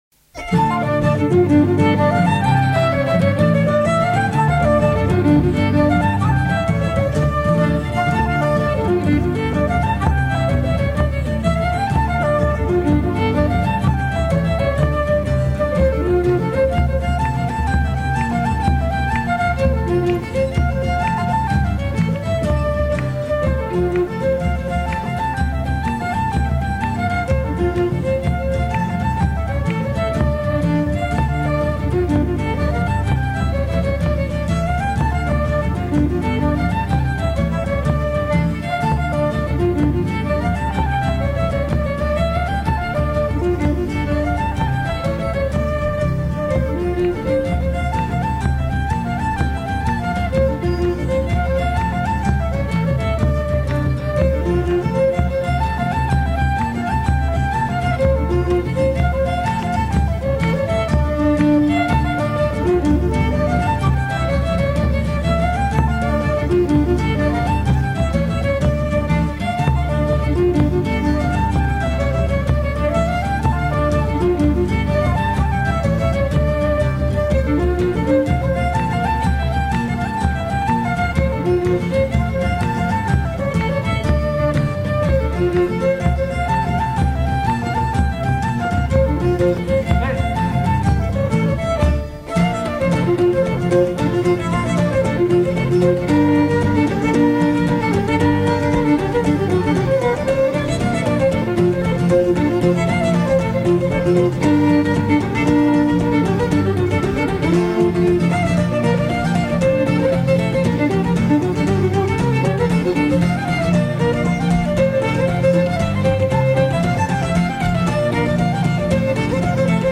violoneux
Pièce musicale éditée